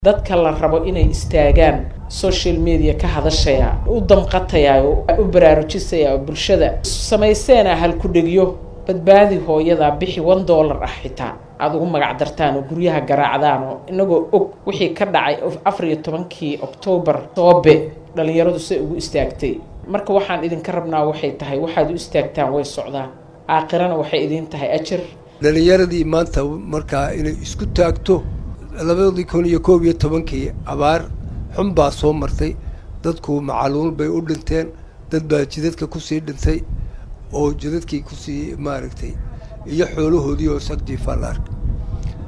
Guddiga Gurmadka abaaraha ee dalka Soomaaliya ayaa kulan wacyigelin ah la qaatay qaar ka mid ah dhallinyarada gobolka Banaadir.
Wasiirka ku xigeennada wasaaradaha tamarta, biyaha iyo beeraha ayaa dhallinyarada ka codsaday inay qayb ka noqdaan gurmadka loo sameynaya dadka ay abaartu saameysay.